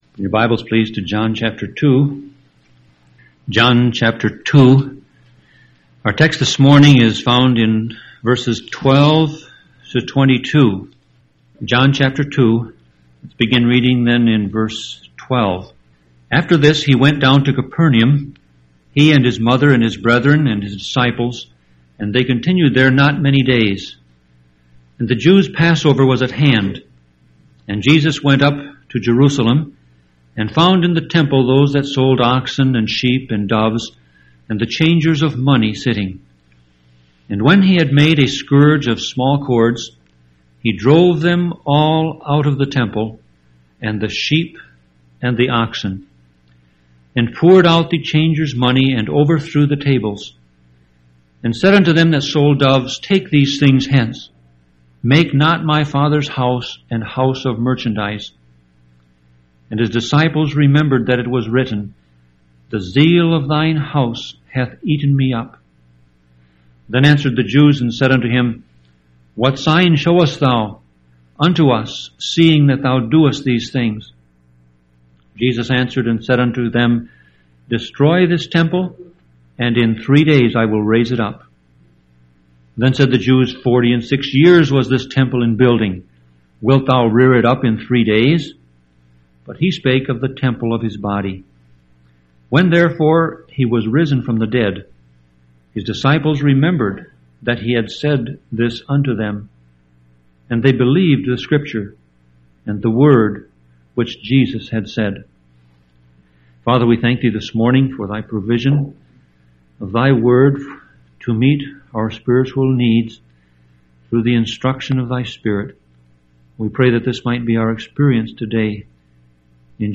Sermon Audio Passage: John 2:12-22 Service Type